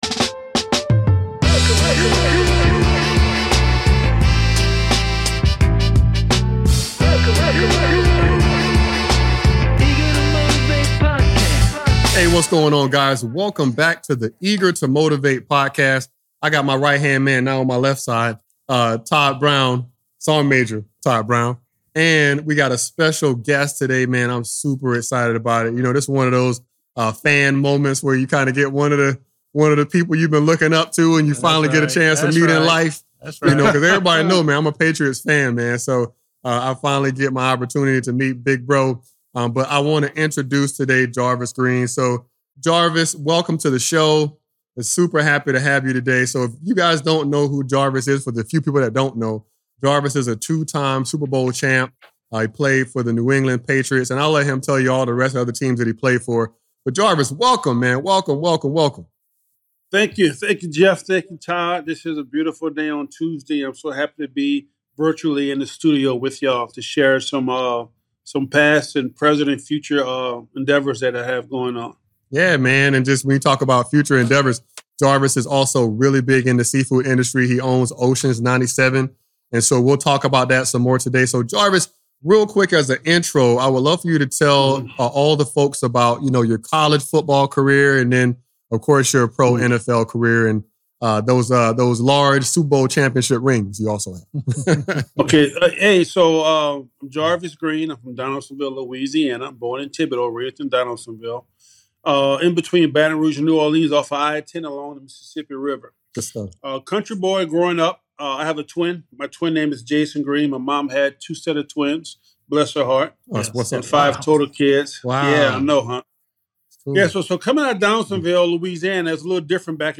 In this episode of Eager to Motivate, two-time Super Bowl Champion Jarvis Green opens up about the highs of his NFL career and the emotional crash that followed.